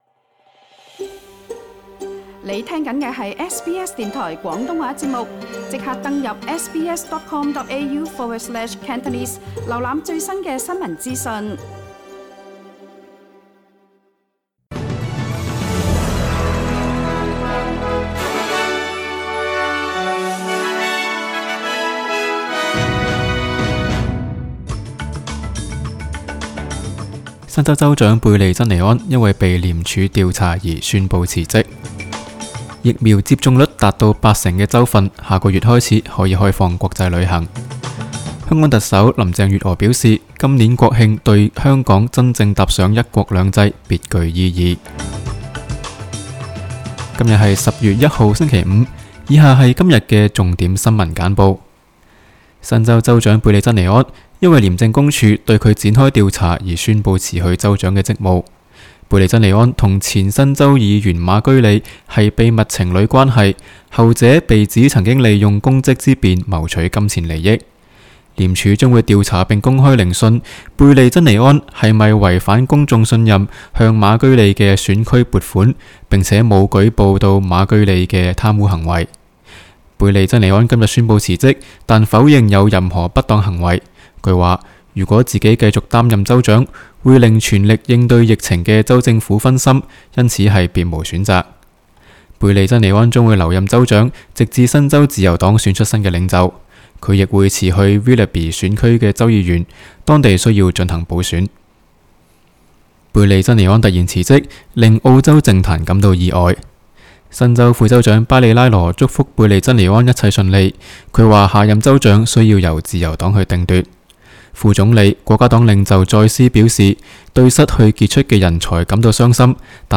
SBS 新聞簡報（10月1日）